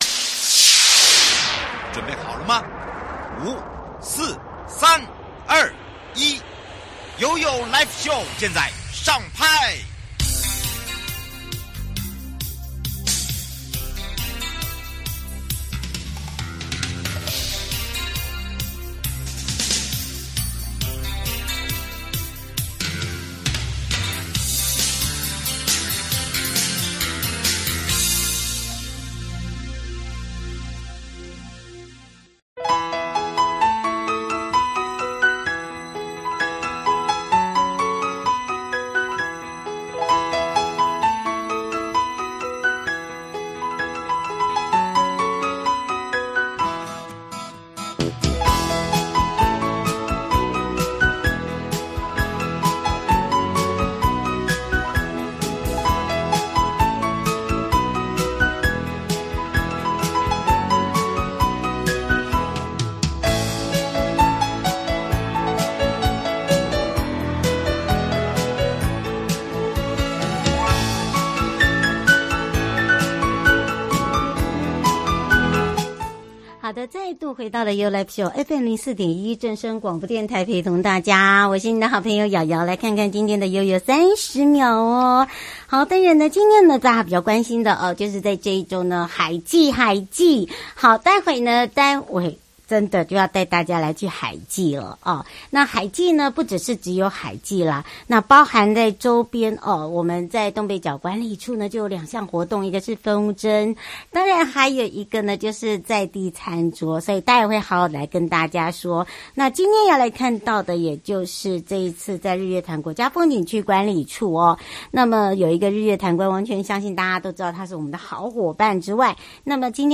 貢寮海祭3亮點！13米變形蟲帳篷、螢光舞台、龍門露營區有11作品 受訪者： 1.